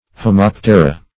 Homoptera \Ho*mop"te*ra\, n. pl. [NL., fr. Gr. ? the same, like